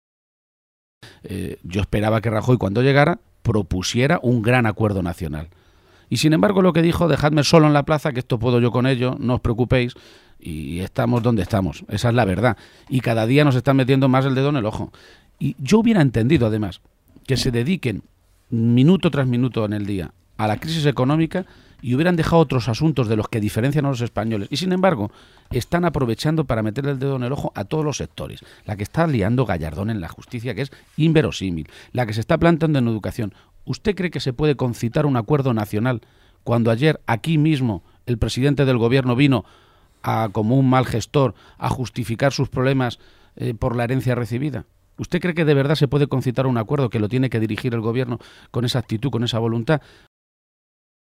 Emiliano García-Page, durante su entrevista en RNE
Cortes de audio de la rueda de prensa